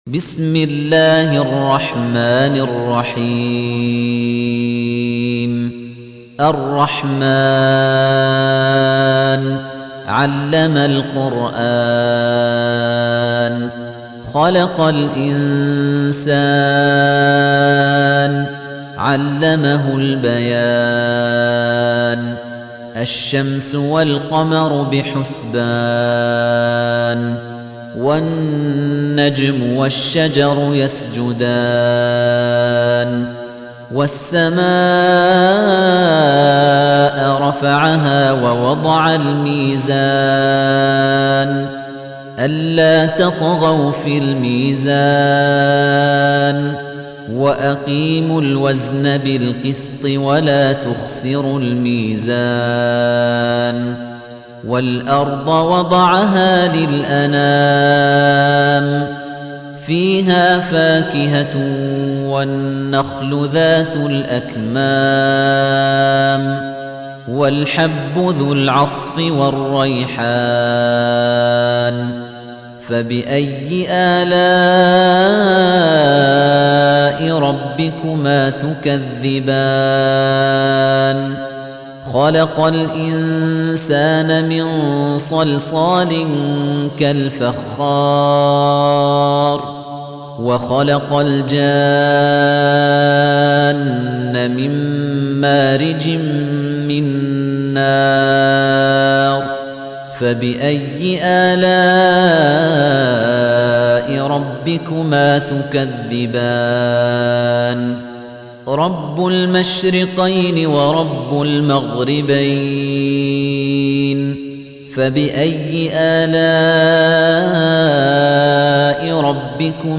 تحميل : 55. سورة الرحمن / القارئ خليفة الطنيجي / القرآن الكريم / موقع يا حسين